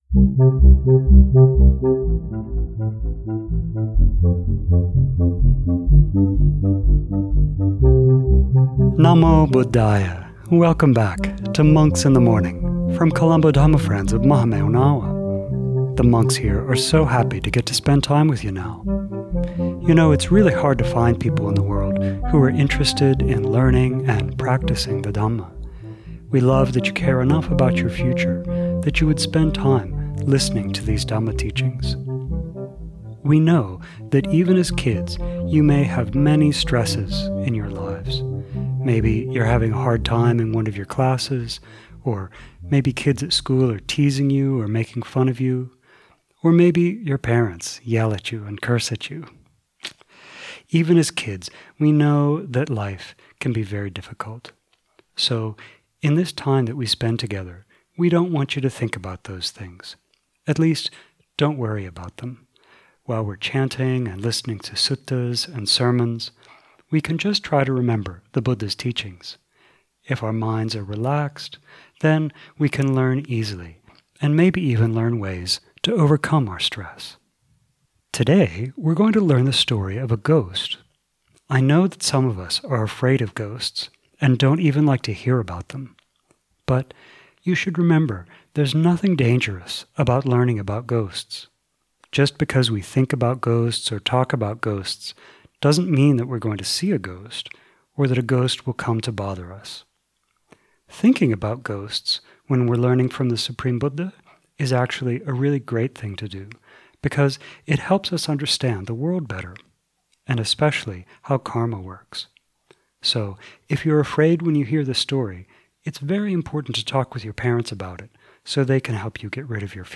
Because of the Arahant Maha Moggallana’s abilities with psychic power, and the Supreme Buddha’s Lokavidu knowledge, today we get to learn about how karma works. Contents 6:20 Sermon on SN ...